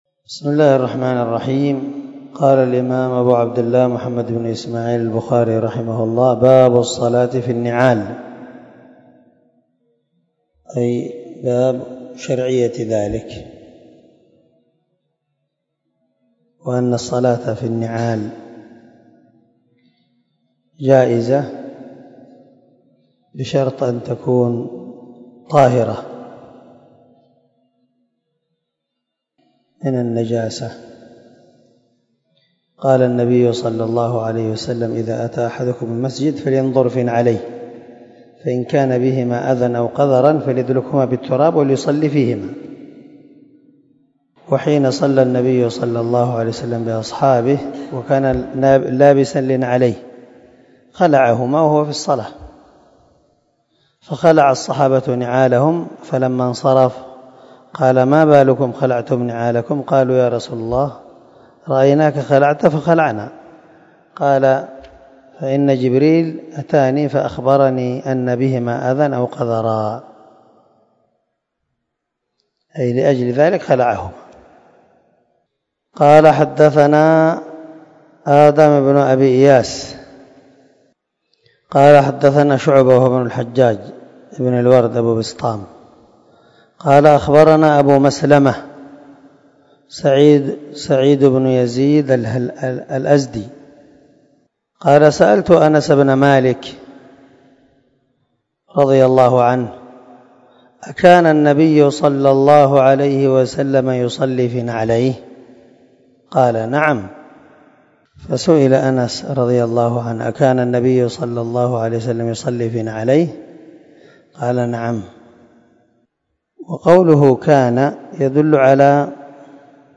296الدرس 29 من شرح كتاب الصلاة حديث رقم ( 386 ) من صحيح البخاري